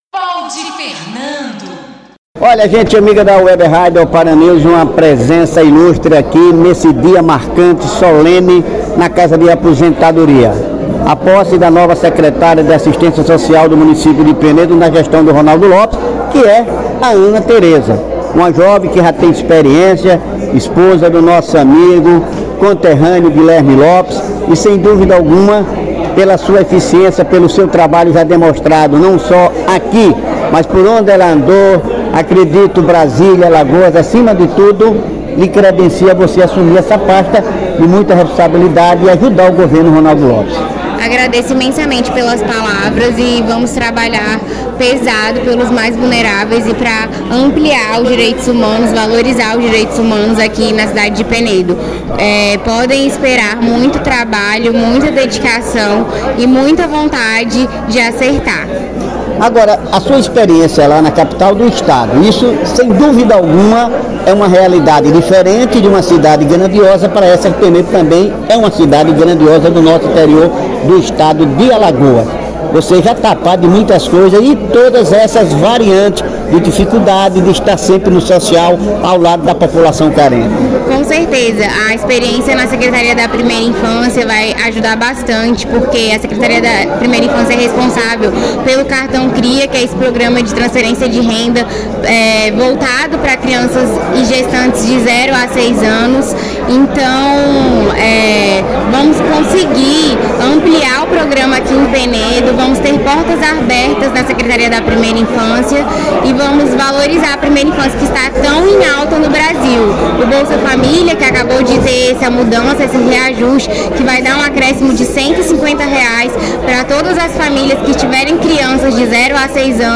O portal de notícias, OparaNews, esteve presente na posse da advogada Ana Teresa Koenigkan Vieira Machado Lopes na Secretaria Municipal de Desenvolvimento Social e Direitos Humanos (SEMASDH).
Na oportunidade foram entrevista a secretária Ana Teresa e o prefeito de Penedo Ronaldo Lopes.